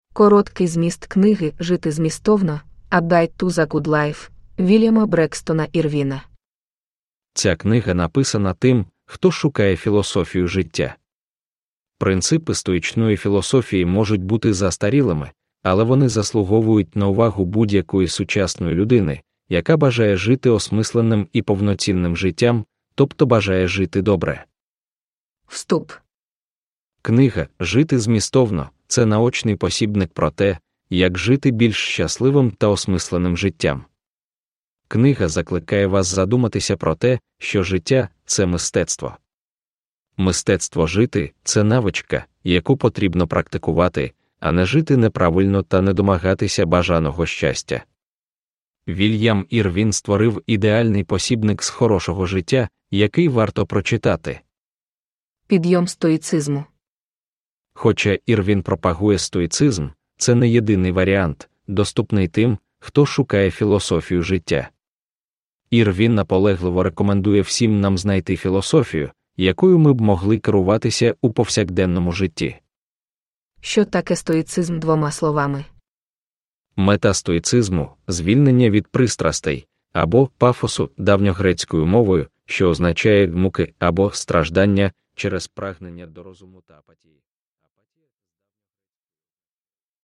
A Guide to the Good Life – Ljudbok – Laddas ner